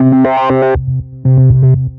Riff Lead.wav